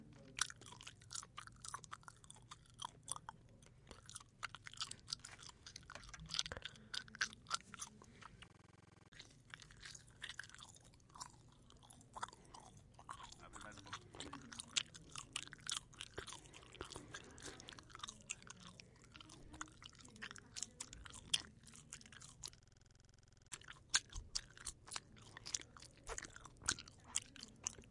吮吸水果
描述：女人咀嚼，吮吸，啜饮和享受一个桃子
Tag: 咀嚼 水果 拍打 柔软 进食